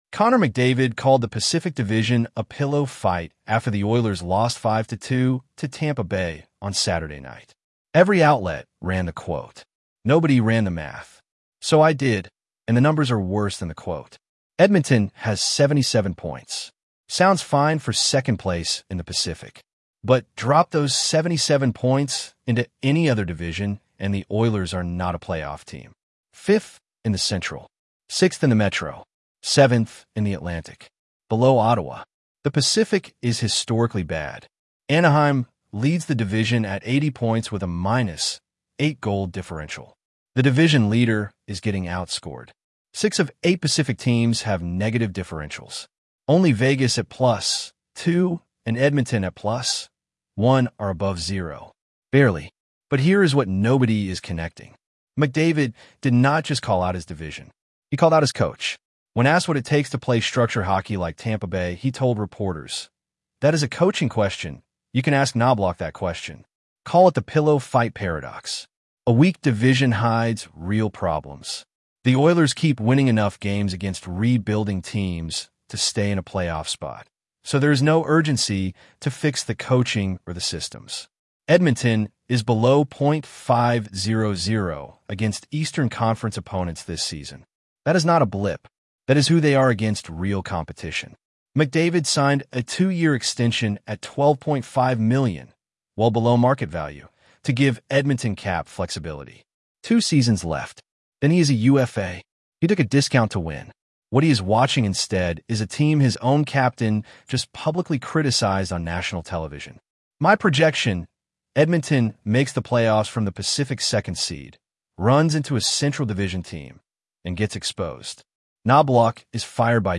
AI Voice